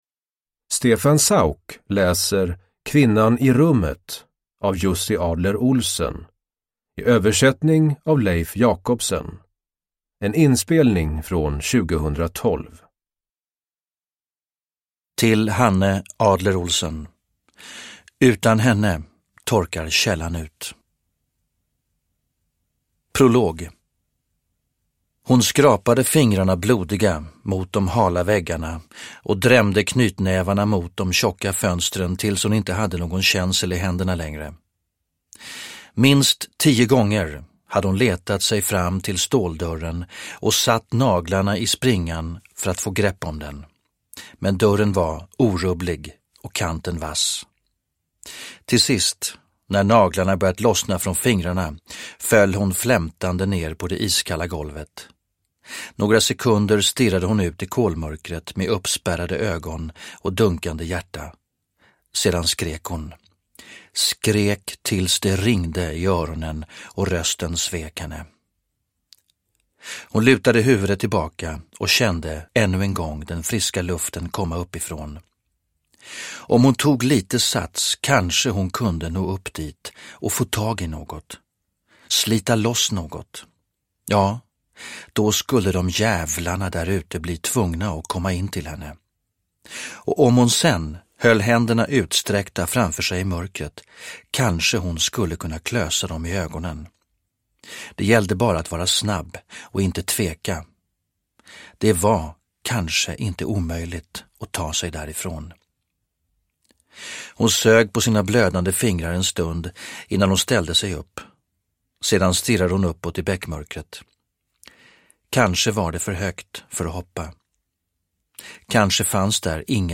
Kvinnan i rummet – Ljudbok – Laddas ner
Uppläsare: Stefan Sauk